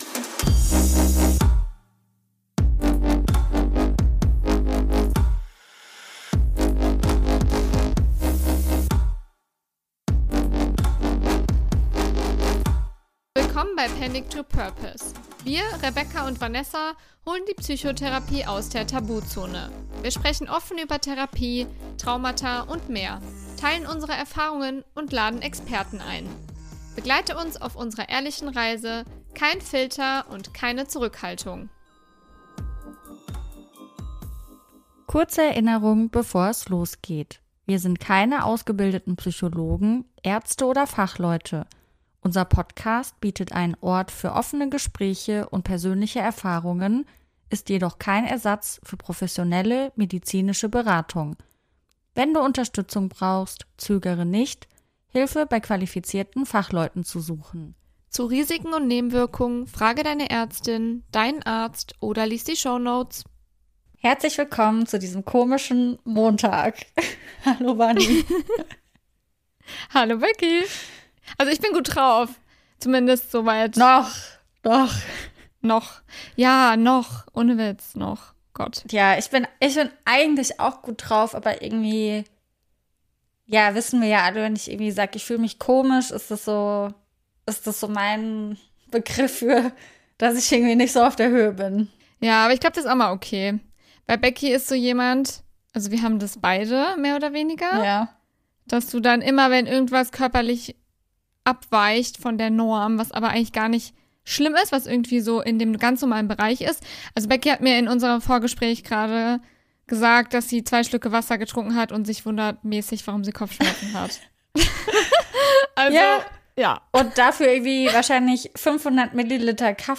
Es wird ehrlich, emotional und an manchen Stellen überraschend: Eine von uns kämpft kurz mit den Tränen, die andere gewinnt wichtige Erkenntnisse für sich selbst.